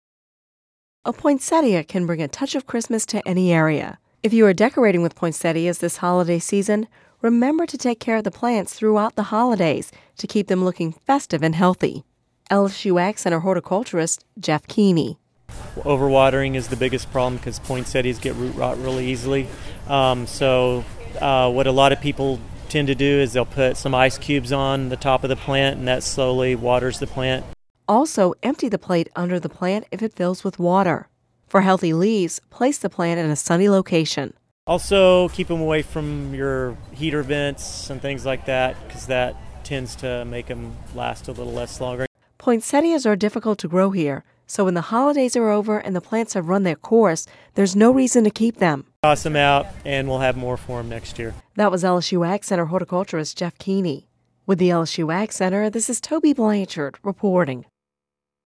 (Radio News 12/20/10) A poinsettia can bring a touch of Christmas to any area. If you are decorating with poinsettias this holiday season, remember to take care of the plants throughout the holidays.